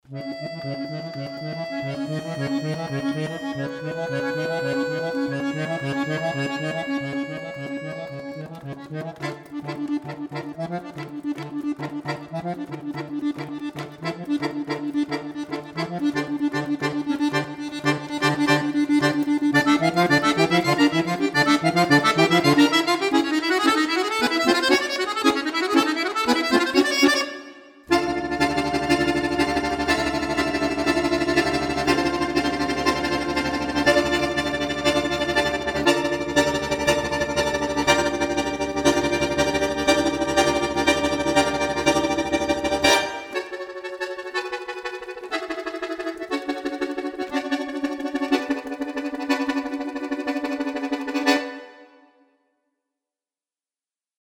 Zeitgenössische Musik / Jazz